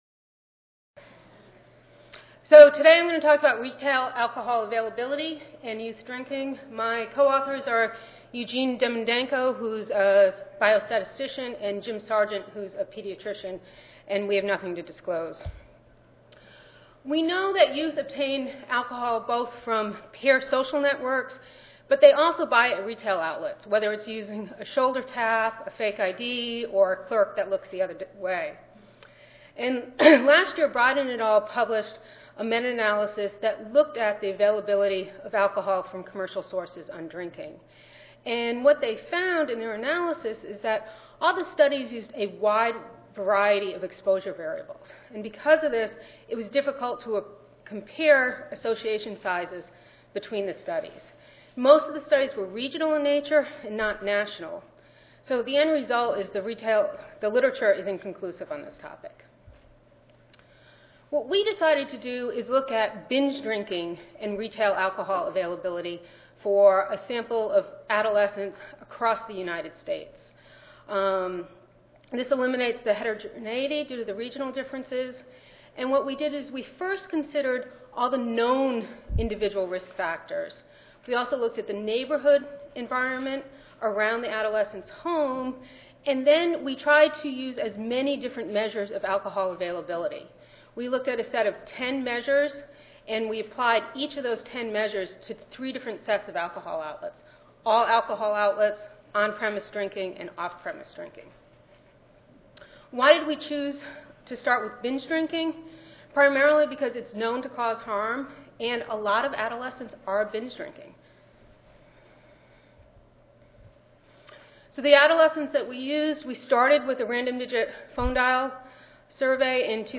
Oral Session